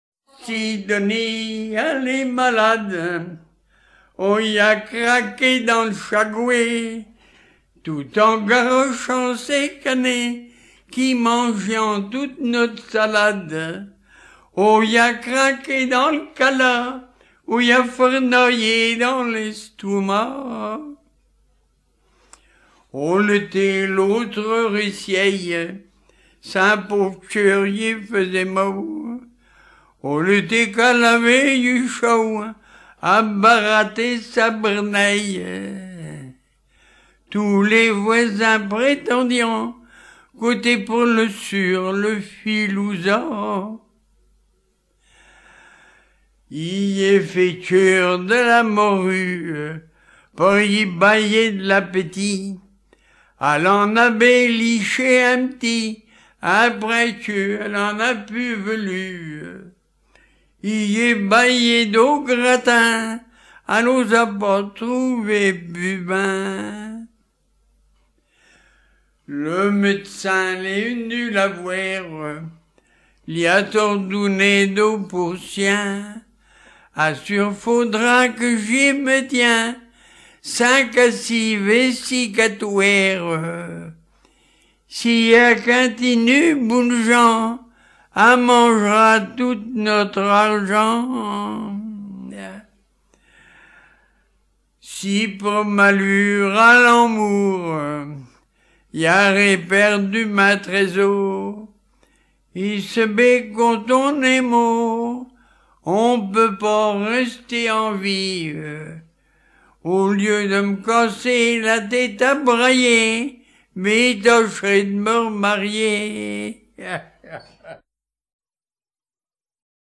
Lettrées patoisantes Résumé : Ma femme est malade, ça lui est arrivé en courant après des canards.
Pièce musicale éditée